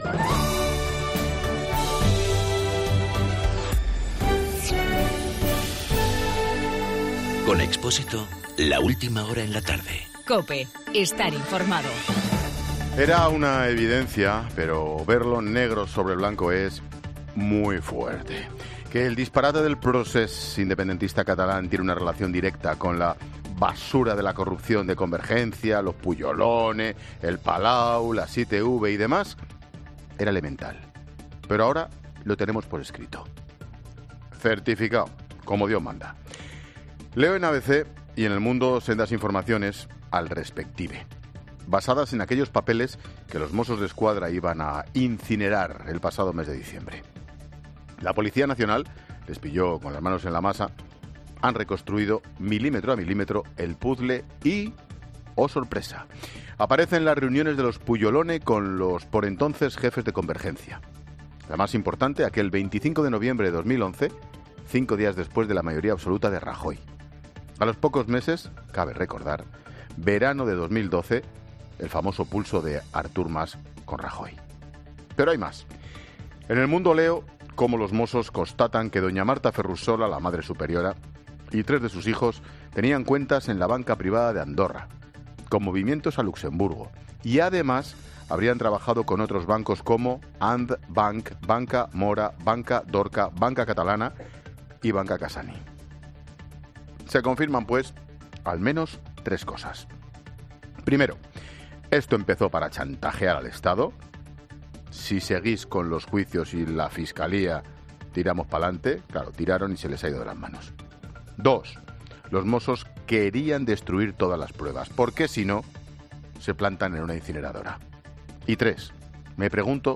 Monólogo de Expósito
El comentario de Ángel Expósito sobre las pruebas que señalan a la familia Pujol metidos en el ajo del procés.